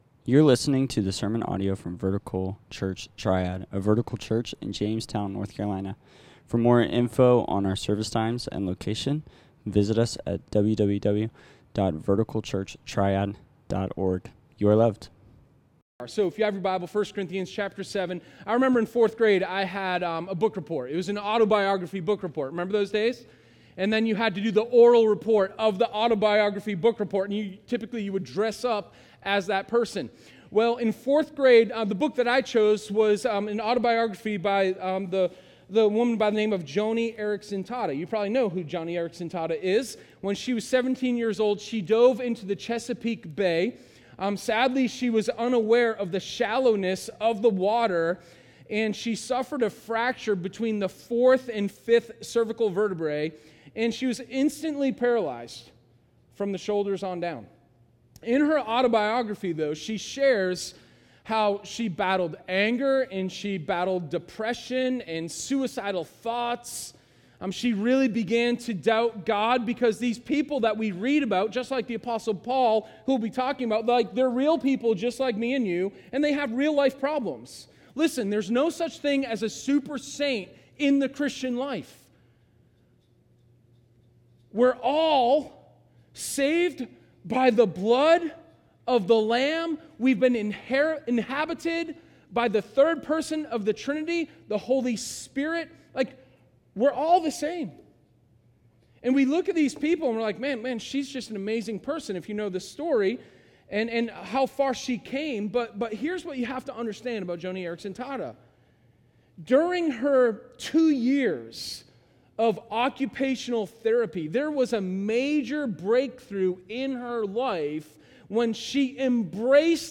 Sermon02_20_Life_On_Mission.m4a